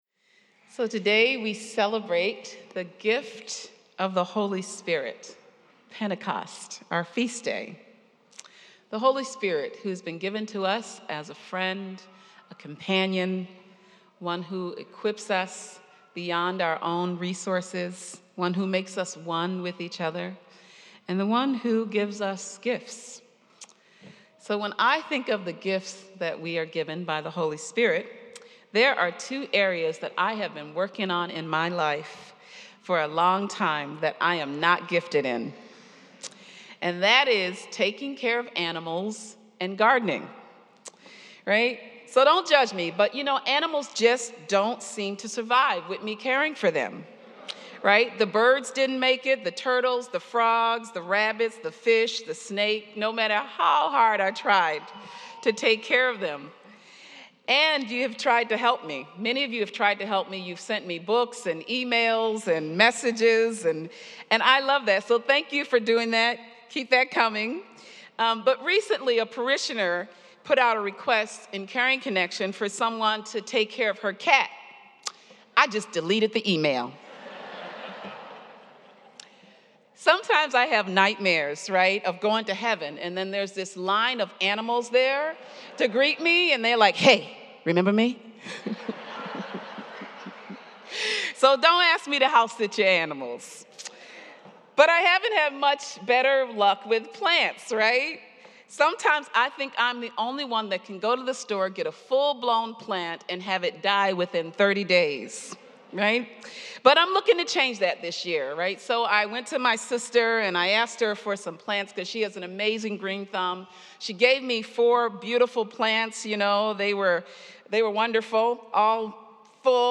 preaches about finding peace in challenging times and the power of the Spirit.